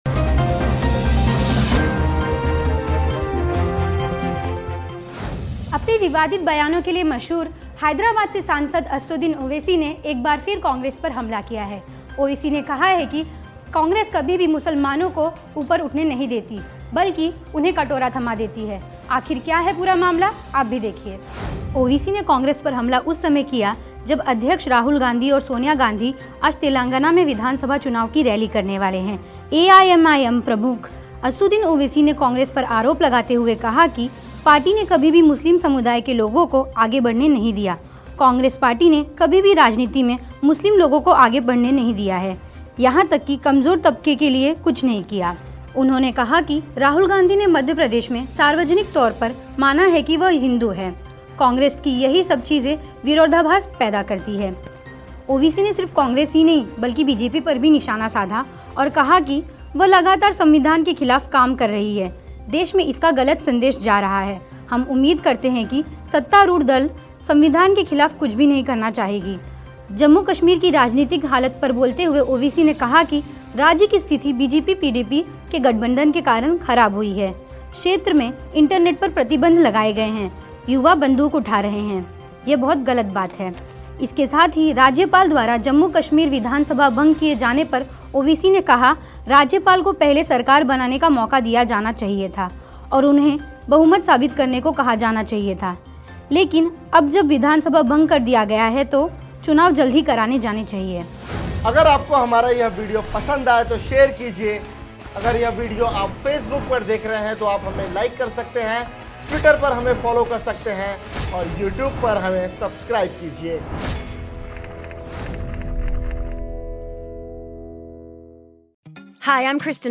न्यूज़ रिपोर्ट - News Report Hindi / ओवैसी ने कहा, कांग्रेस ने पकड़ाया मुसलमानों को कटोरा !